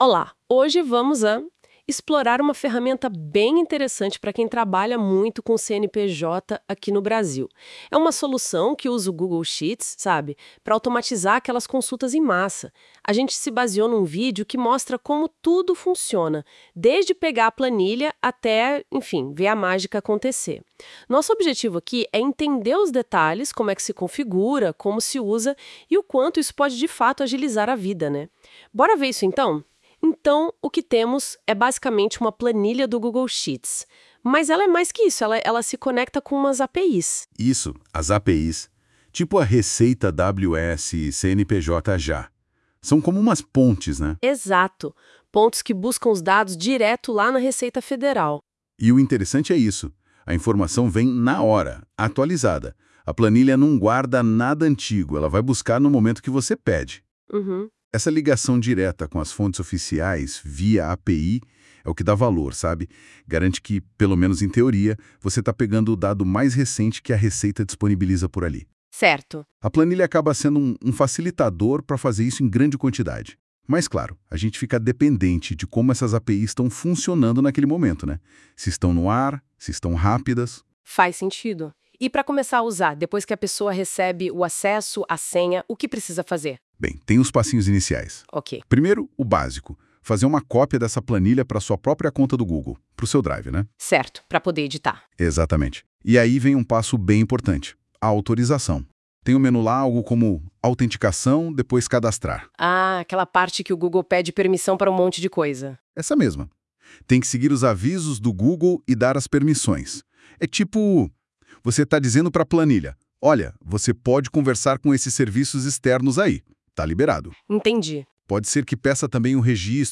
Veja como funciona a Consulta CNPJ Ouça o que o G o o g l e AI kLM diz sobre o Consulta CNPJ Automático Descubra como consultar CNPJs automaticamente direto no Google Sheets.